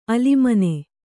♪ alimane